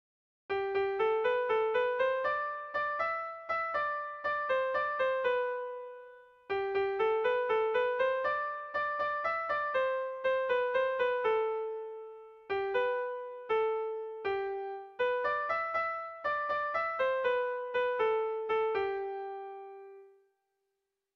Erlijiozkoa
A1A2BD